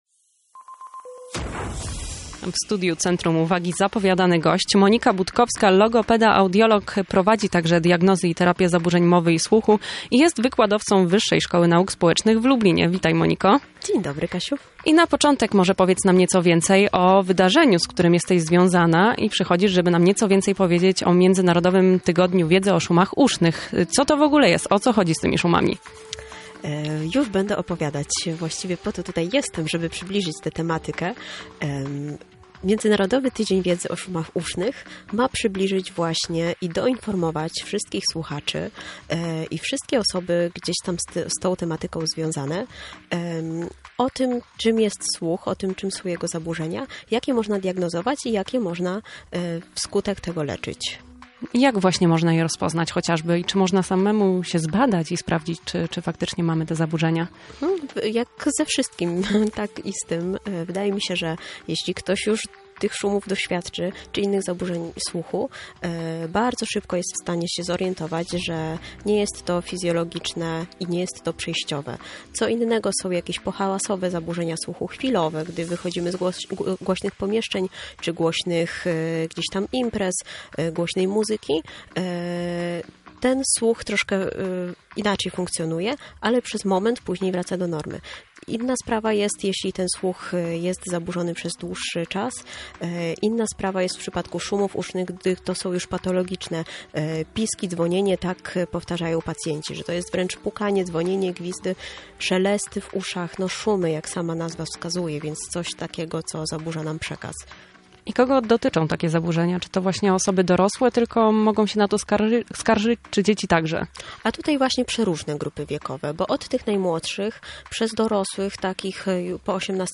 Na pytania czym są szumy uszne i jak je można rozpoznać odpowiada specjalista: